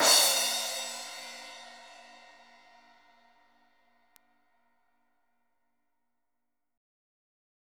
CRASH 2   -L.wav